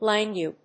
langue /lάːŋg/
発音記号
• / lάːŋg(米国英語)